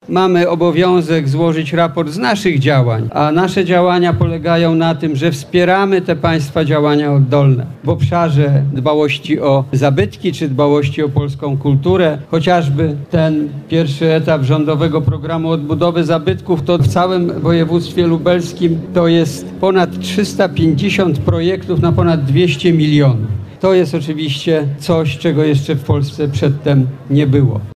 12,5 mln zł dla powiatu łukowskiego na 12 projektów – minister kultury i dziedzictwa narodowego Piotr Gliński podsumował w Łukowie pierwszy etap rządowego programu odbudowy zabytków.